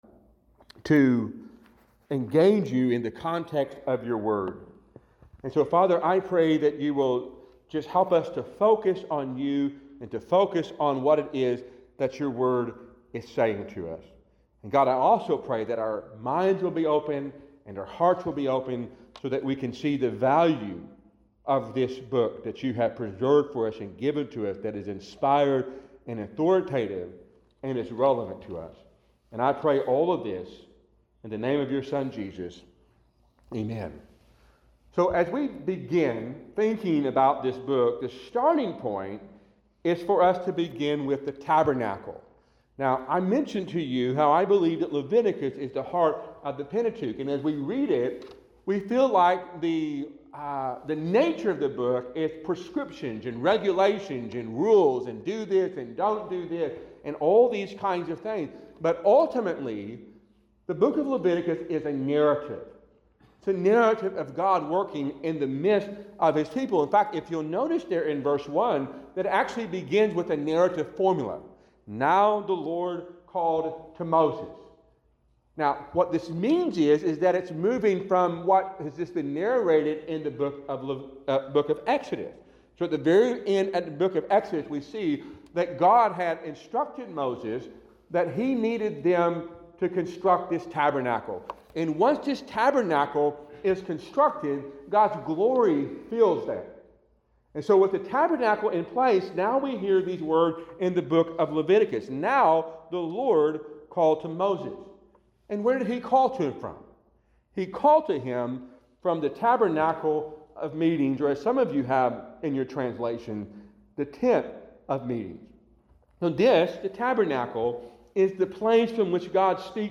A sermon from Leviticus 1:1-2.